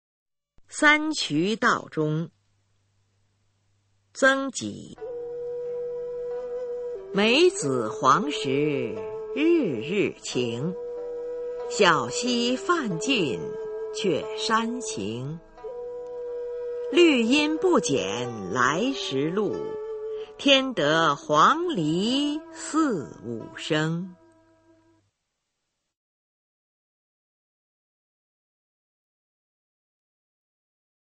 [宋代诗词诵读]曾几-三忂道中（女） 宋词朗诵